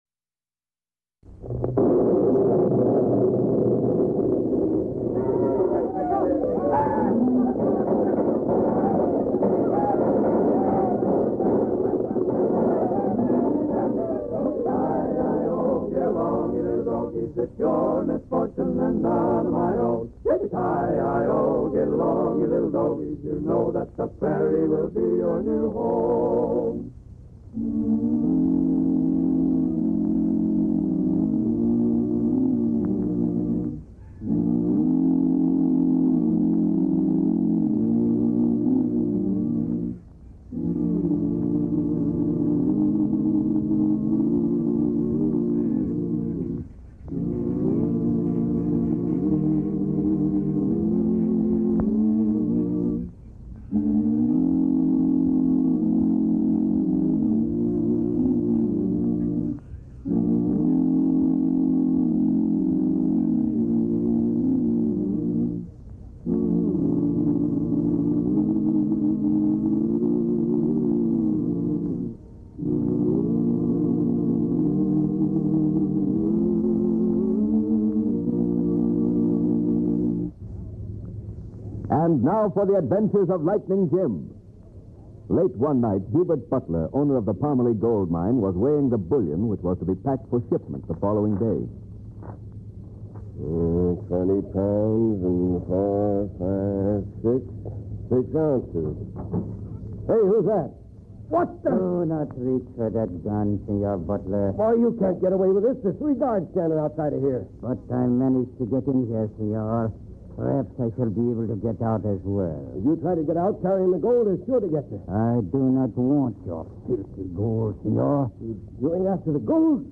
"Lightning Jim" was a popular old-time radio show that featured the adventures of U.S. Marshal Lightning Jim Whipple. - The character of Lightning Jim, along with his trusty horse Thunder and deputy Whitey Larson, became iconic figures in Western radio drama.